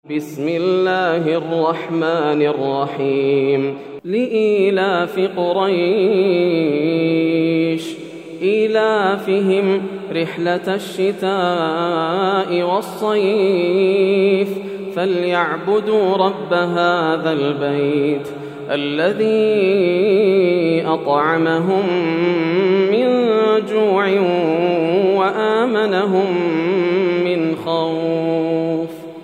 سورة قريش > السور المكتملة > رمضان 1431هـ > التراويح - تلاوات ياسر الدوسري